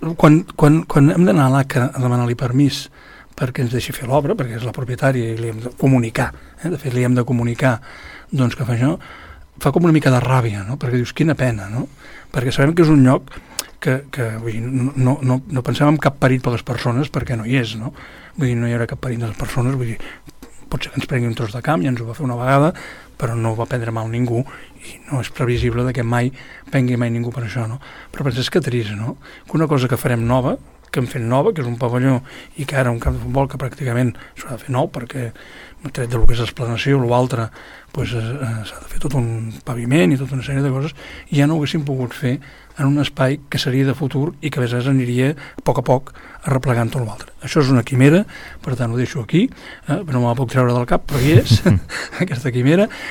L’alcalde de l’Espluga ho va expressar durant l’entrevista d’aquest diumenge a l’Ajuntament a Casa quan feia balanç de l’estat de les obres de construcció del nou pavelló.